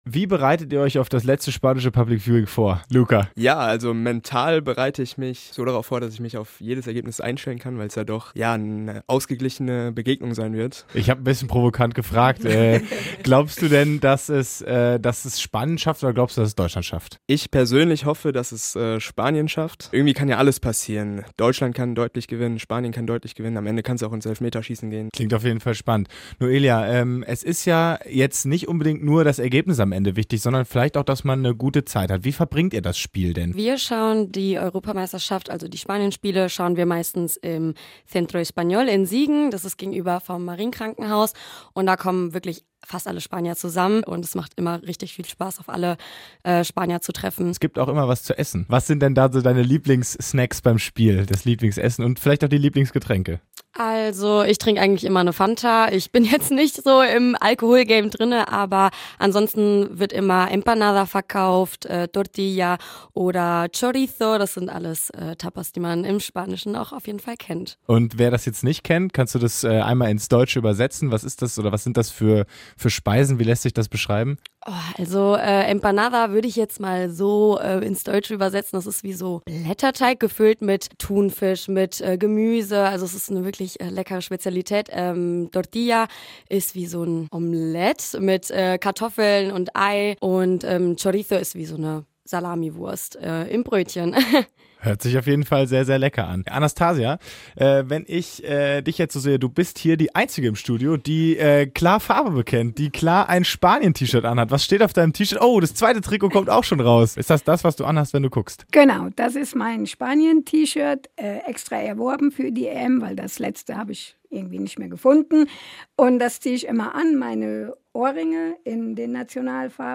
Im Interview bei Radio Siegen am Nachmittag